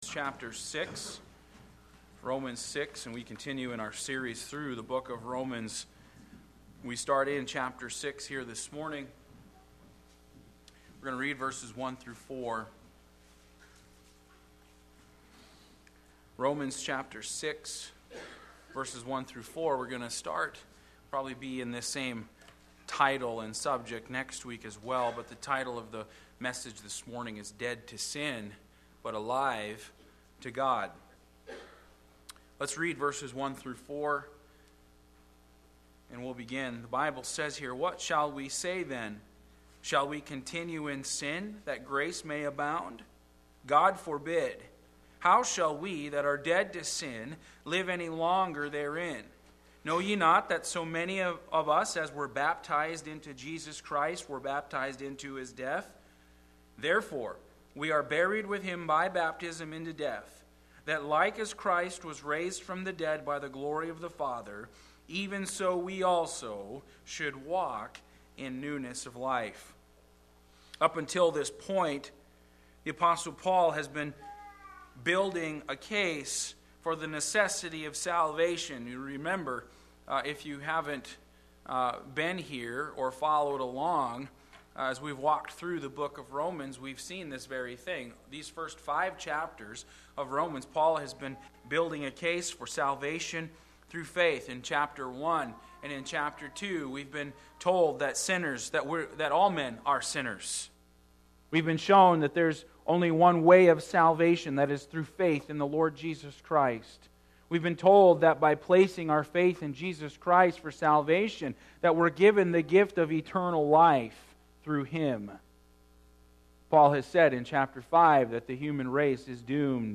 Passage: Romans 6:1-15 Service Type: Sunday Morning